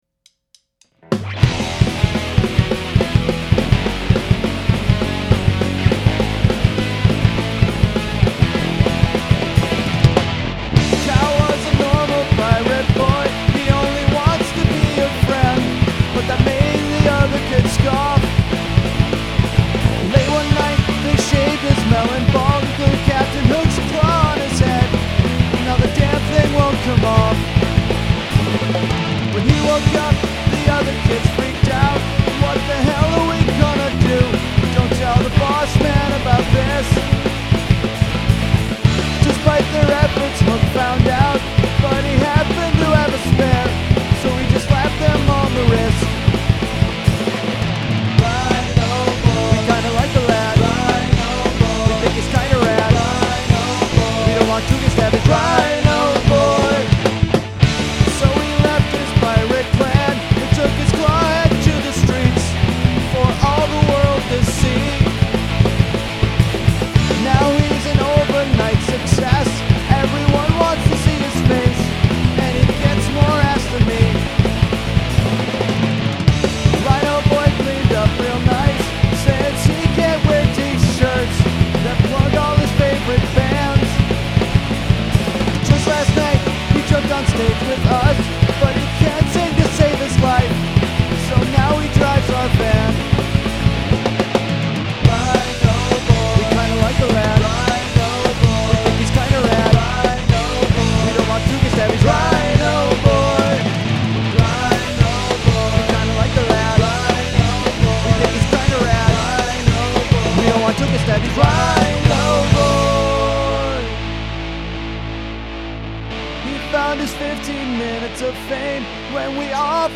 Enjoy the demo!
All things by me.